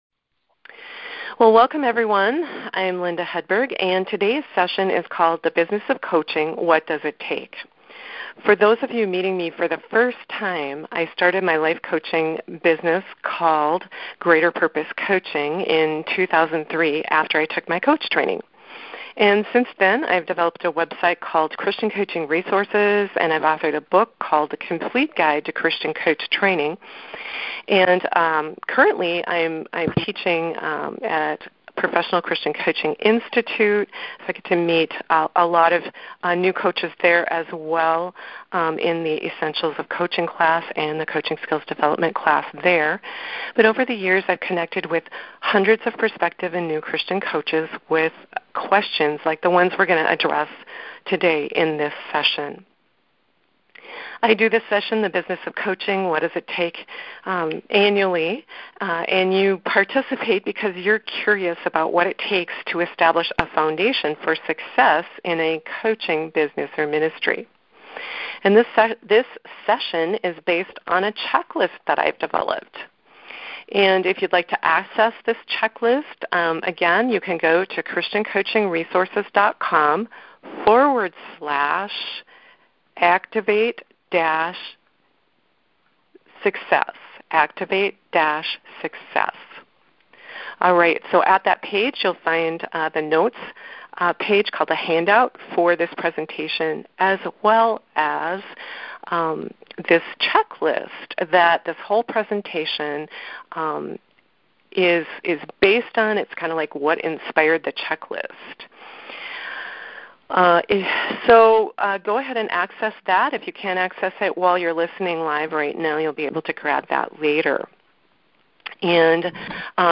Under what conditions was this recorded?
The recording of the March 21 2019 teleseminar is here: